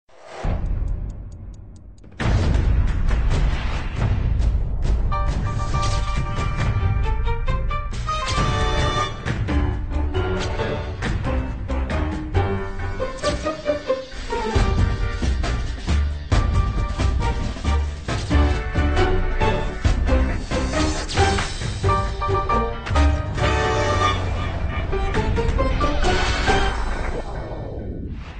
un petit générique :